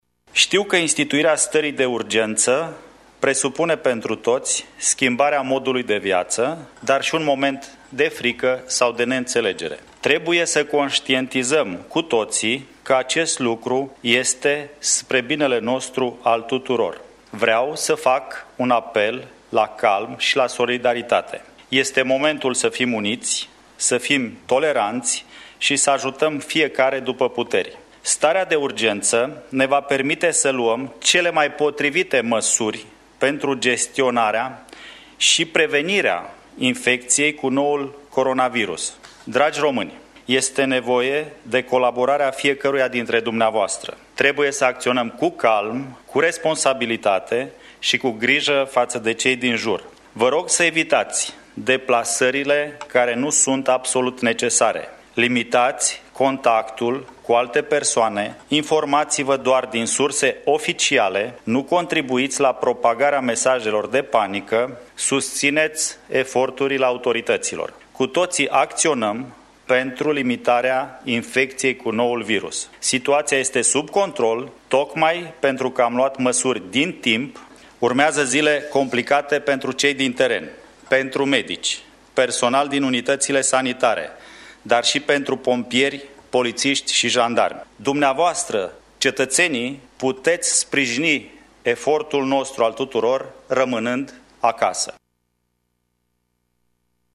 Transcrierea conferinței de presă a fost realizată de RADOR.